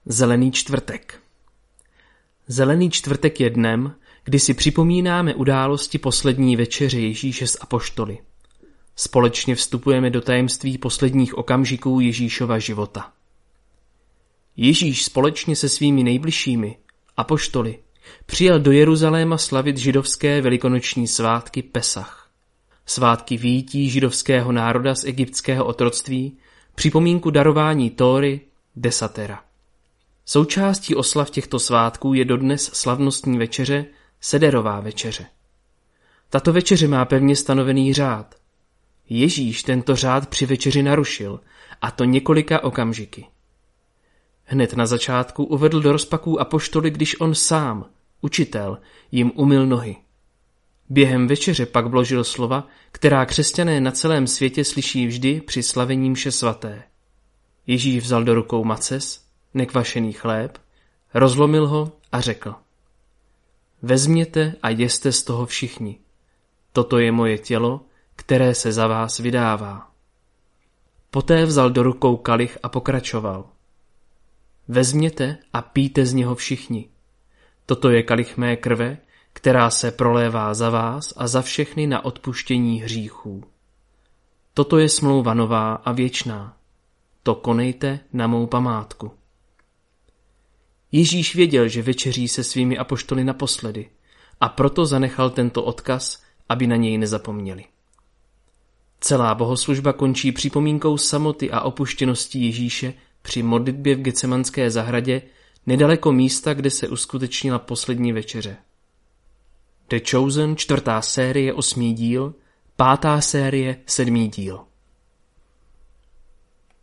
Lektorské úvody k triduu